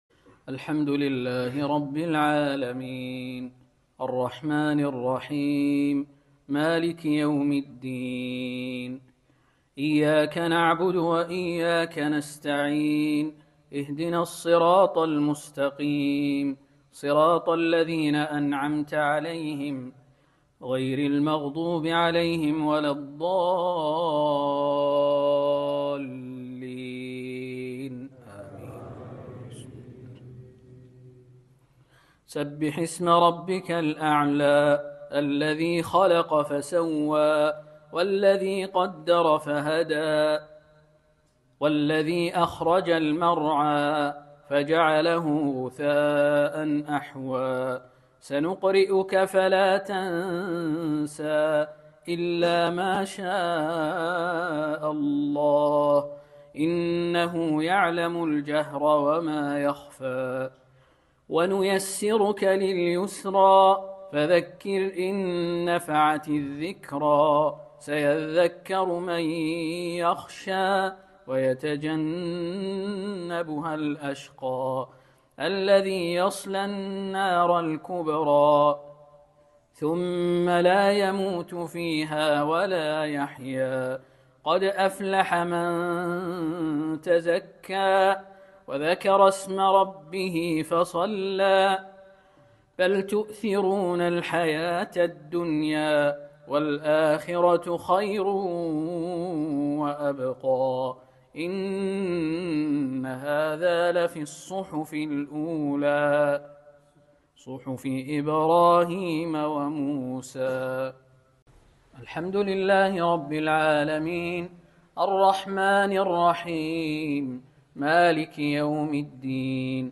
صلاة الجمعة 22 ربيع الآخر 1446هـ بجامع هادروفيتش بجمهورية الجبل الأسود